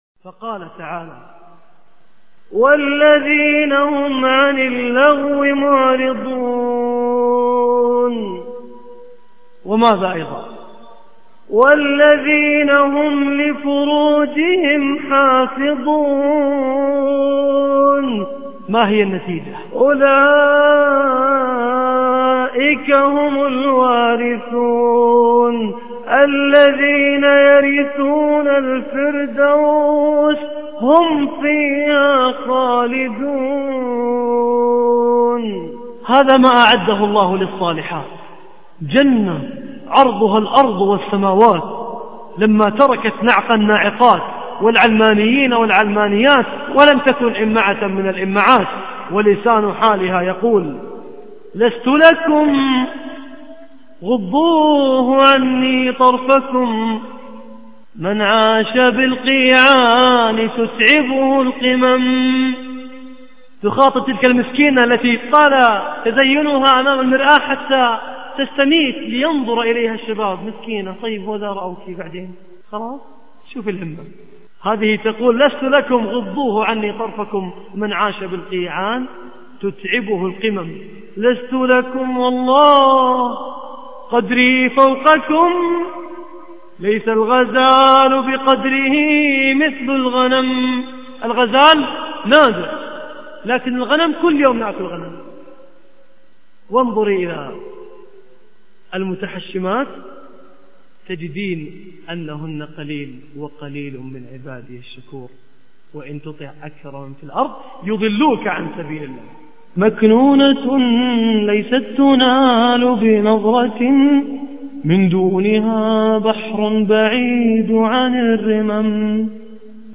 إقتطاف من محاضرة رائعة